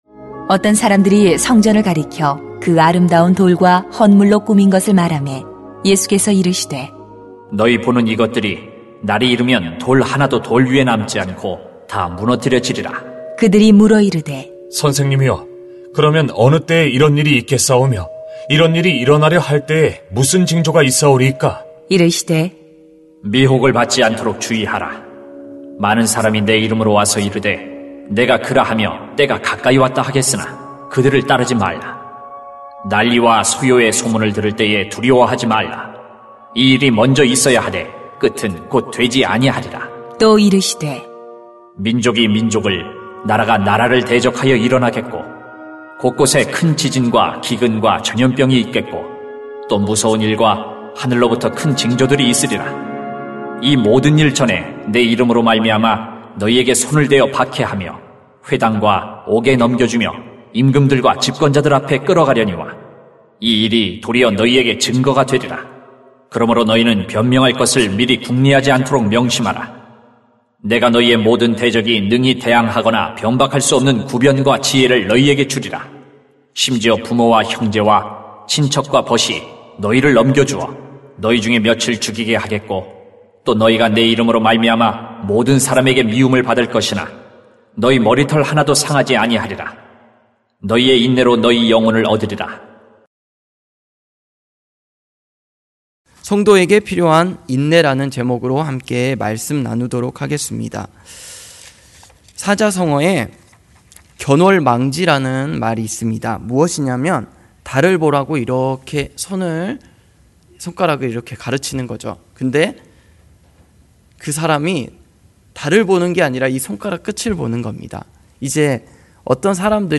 [눅 21:5-19] 성도에게 필요한 인내 > 새벽기도회 | 전주제자교회